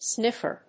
音節sniff･er発音記号・読み方snífər
• / ˈsnɪfɝ(米国英語)
• / ˈsnɪfɜ:(英国英語)